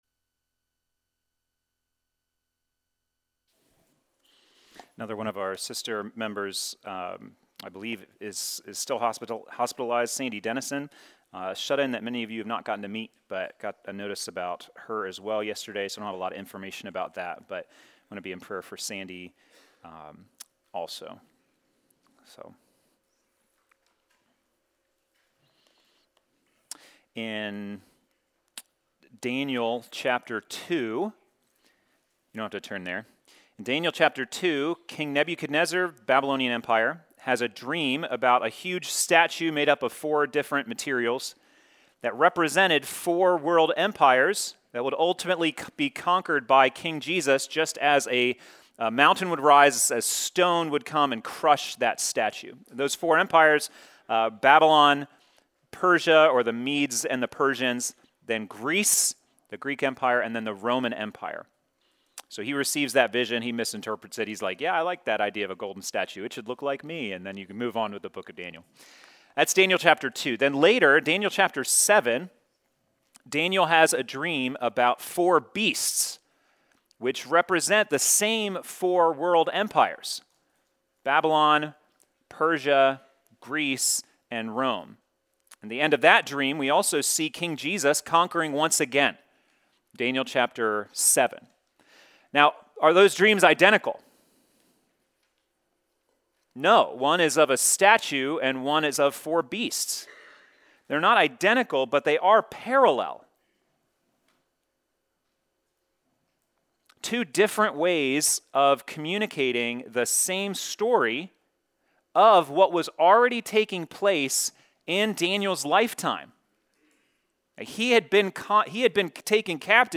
The weekly sermons from Risen King Church in Hurricane, WV